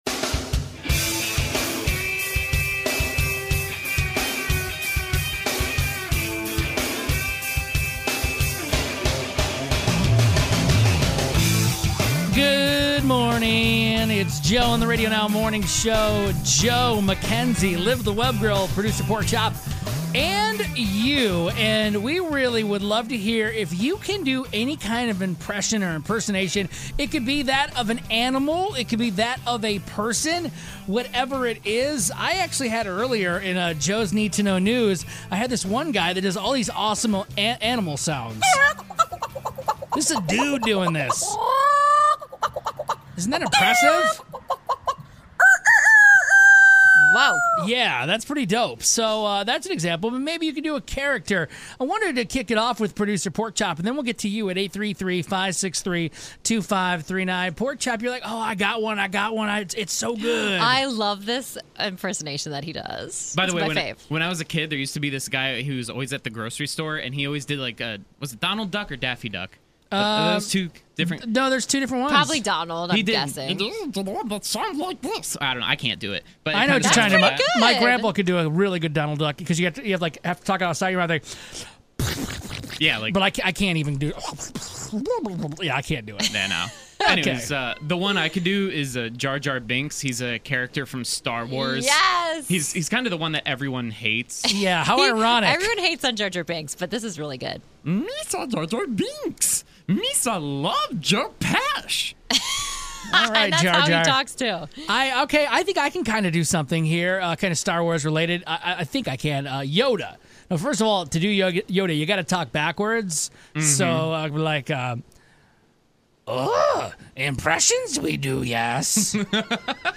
We do our best impressions & we have listeners call in to show us what they've got.